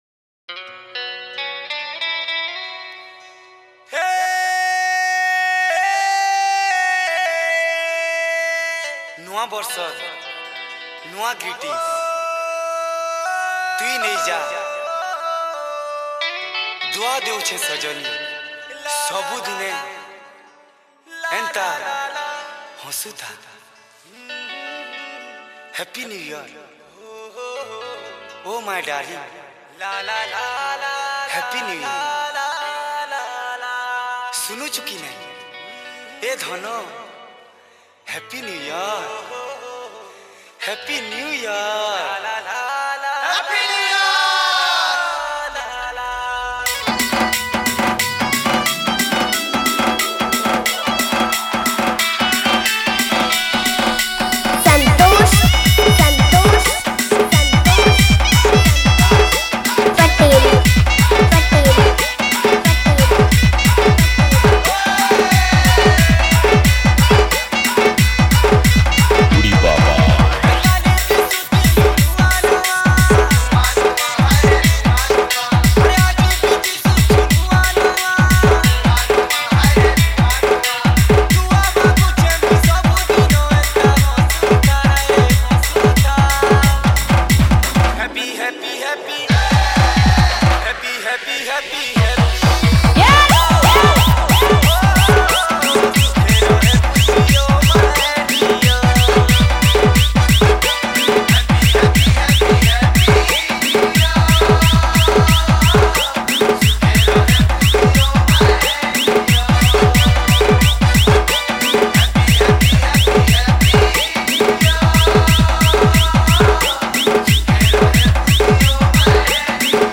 New Year Special Dj Remix